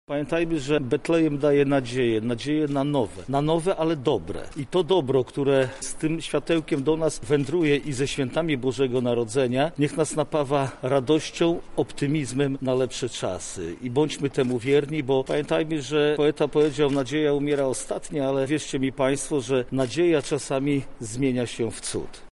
-mówi marszałek województwa lubelskiego Jarosław Stawiarski.